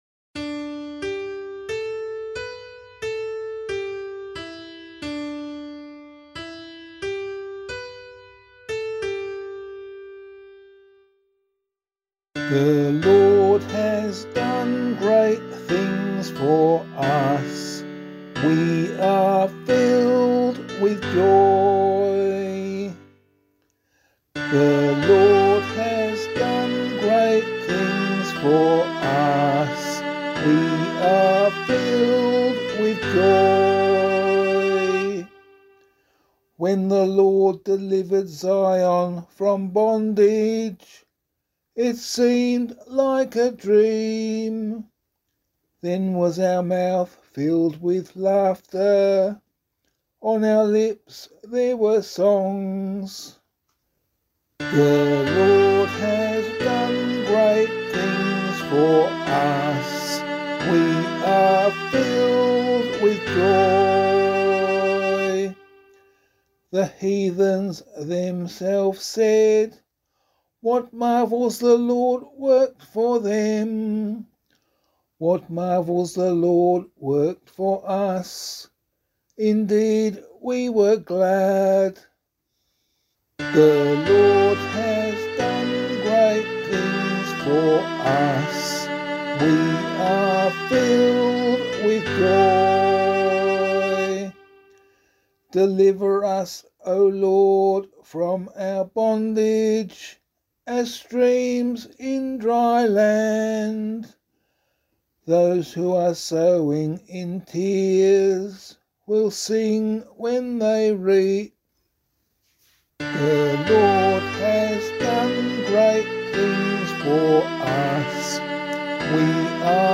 017 Lent 5 Psalm C [LiturgyShare 7 - Oz] - vocal.mp3